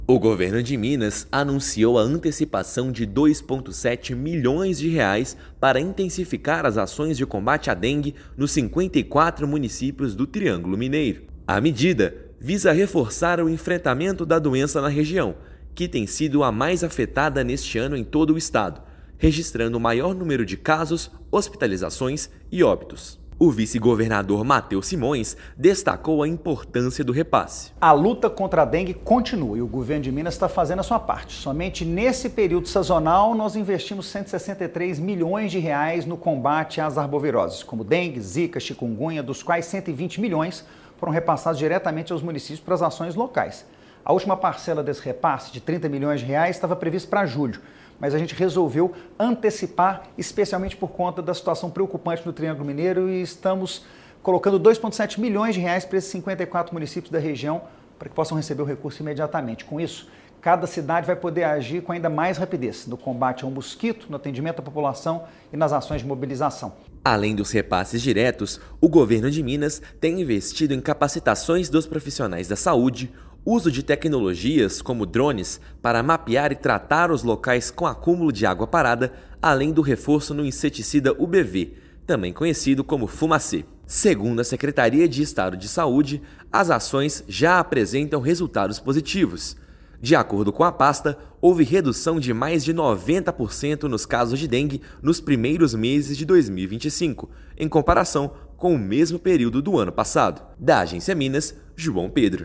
[RÁDIO] Governo de Minas vai adiantar R$ 2,7 milhões para enfrentamento da dengue no Triângulo Mineiro
Recurso estava previsto para ser repassado em julho, parte dos esforços estaduais no combate à doença e outras arboviroses. Ouça matéria de rádio.